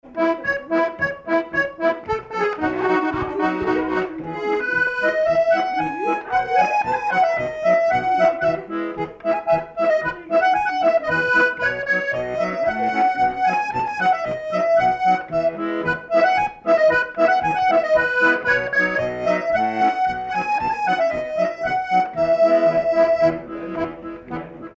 Marche
danse : marche
circonstance : bal, dancerie
Pièce musicale inédite